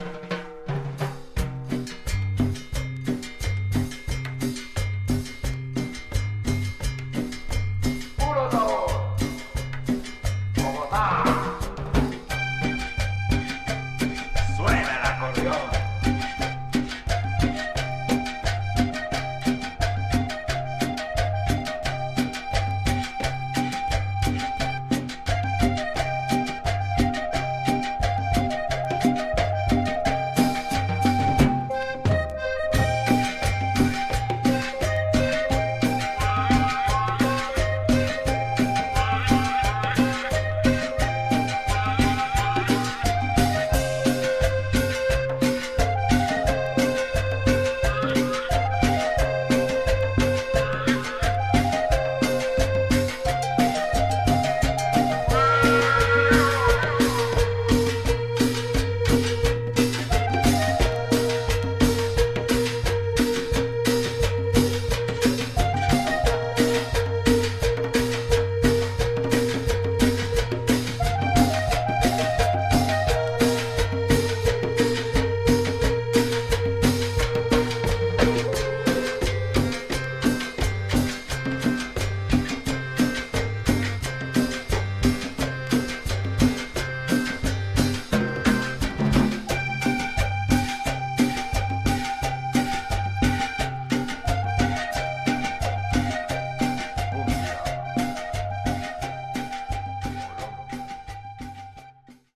Tags: Colombia , Bogotá , Psicodelico
Bonkers cumbias con acordeón with this double sider release.